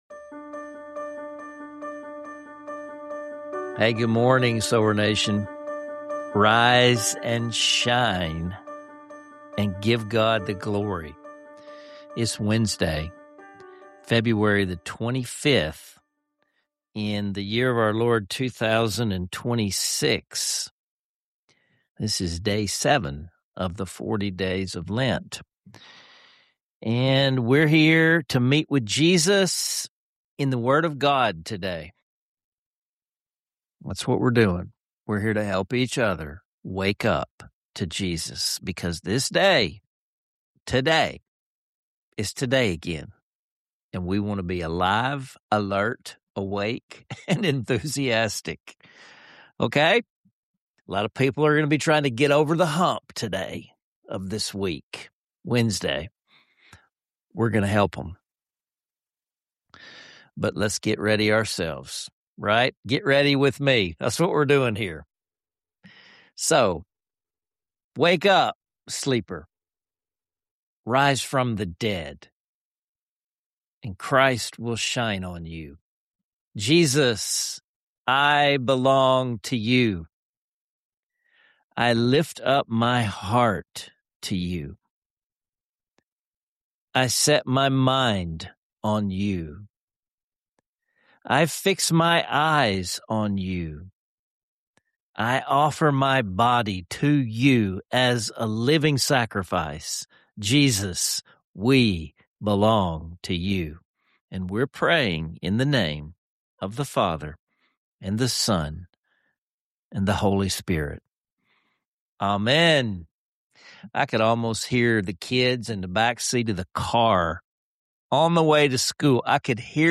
Subscribe for more inspiring conversations like this!